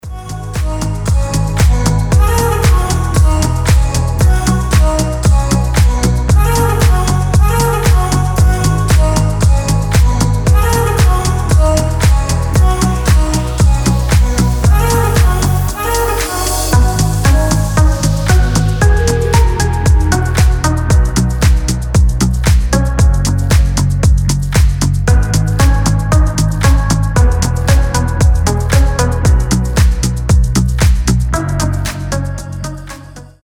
• Качество: 320, Stereo
забавные
милые
house
Melodic house